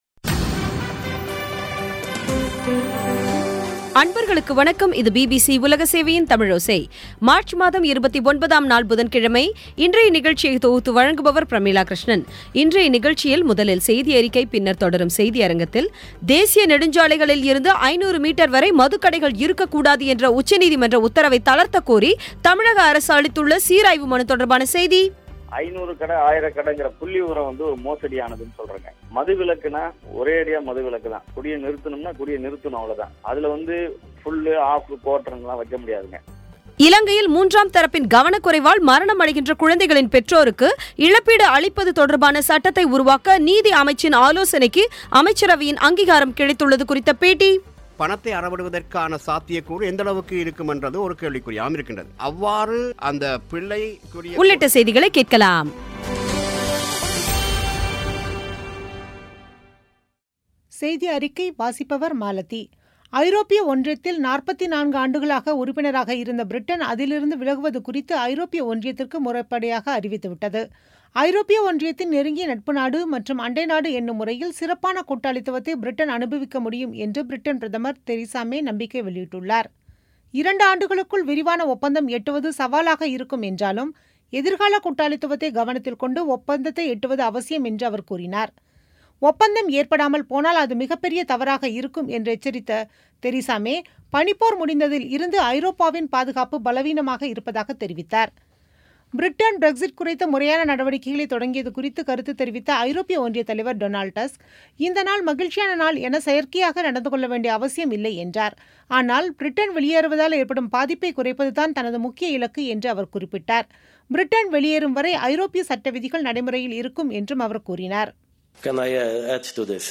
இலங்கையில் 3ம் தரப்பின் கவனக்குறைவால் மரணமடைகின்ற குழந்தைகளின் பெற்றோருக்கு இழப்பீடு அளிப்பது தொடர்பான சட்டத்தை உருவாக்க நீதி அமைச்சின் ஆலோசனைக்கு அமைச்சரவையின் அங்கீகாரம் கிடைத்துள்ளது குறித்த பேட்டி உள்ளிட்ட செய்திகளை கேட்கலாம்